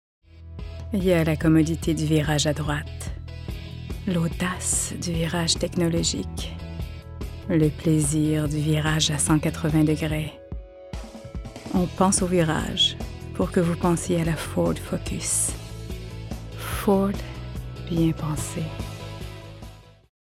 Voix hors champs
Ford-Sensuelle